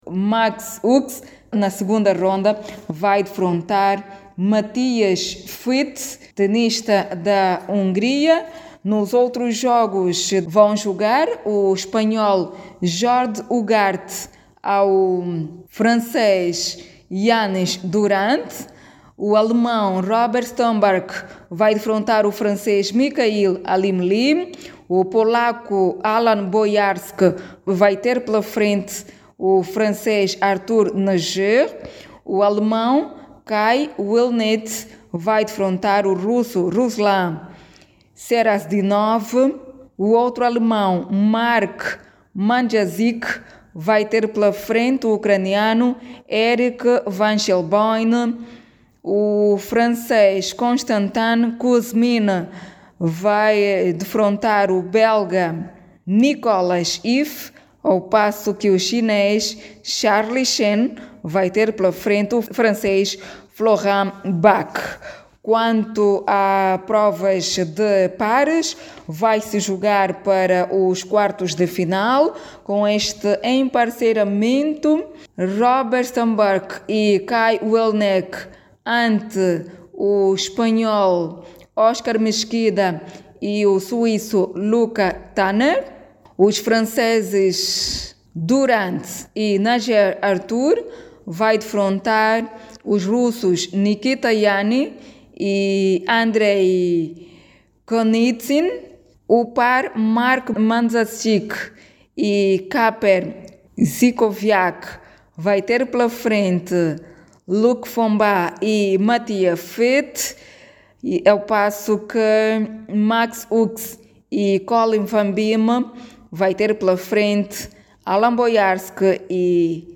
O torneio Internacional de Ténis, M-15 que decorre na Academia Kikuxi Vilas Club, prossegue esta quinta-feira, 20, com disputa da 2ª jornada. Saiba mais dados no áudio abaixo com a repórter